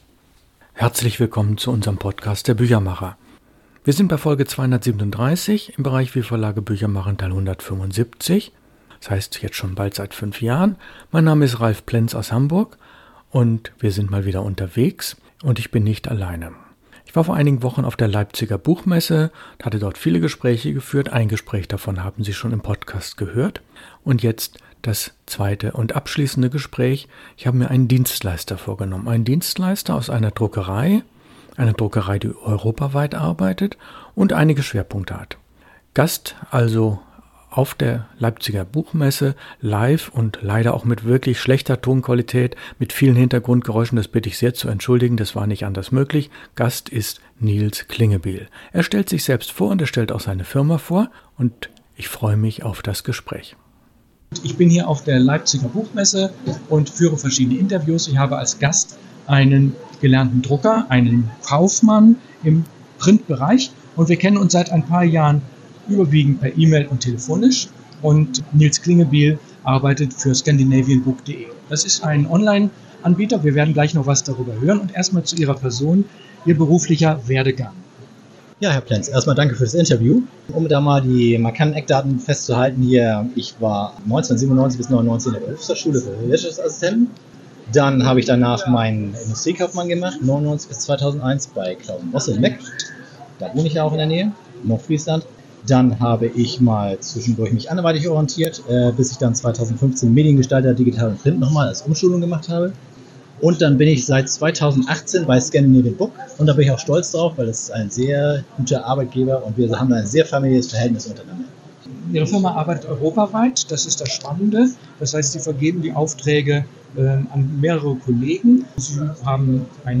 in einem Gespräch auf der Leipziger Buchmesse